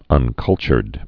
(ŭn-kŭlchərd)